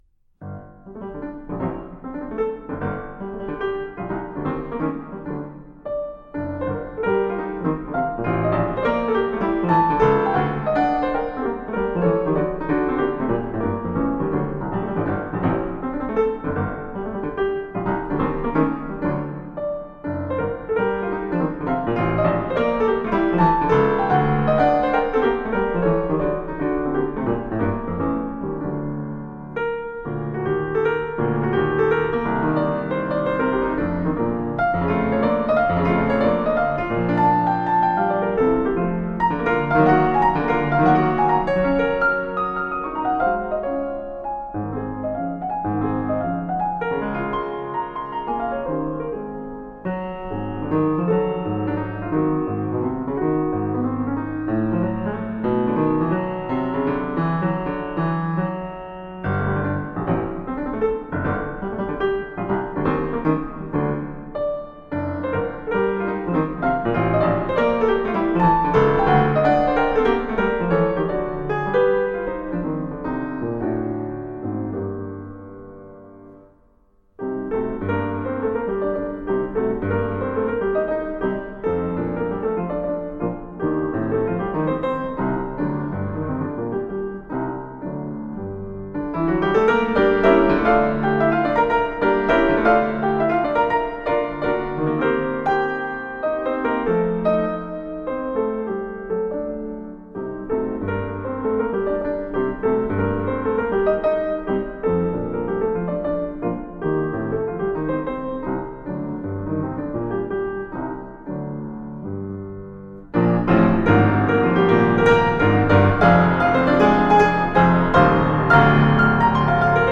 Virtuoso piano.